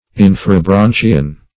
Search Result for " inferobranchian" : The Collaborative International Dictionary of English v.0.48: Inferobranchian \In`fe*ro*bran"chi*an\, n. (Zool.)